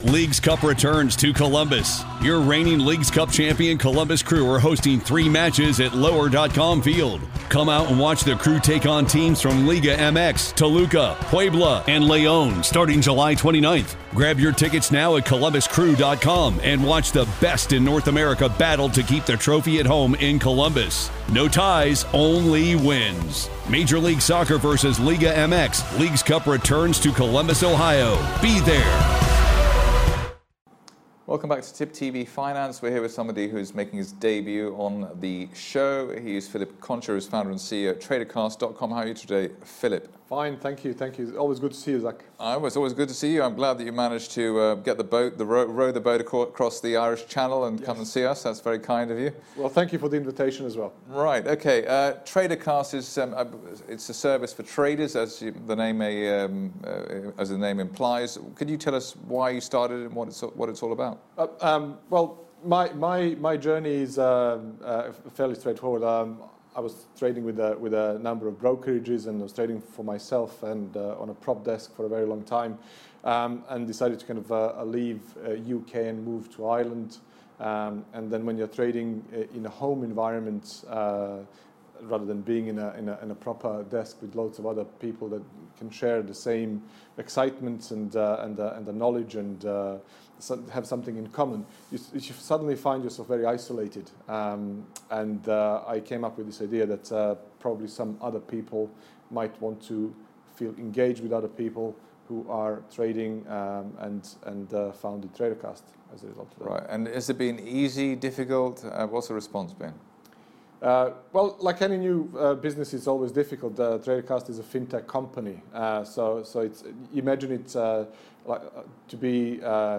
TraderCast: CEO Interview - Helping traders to make informed decisions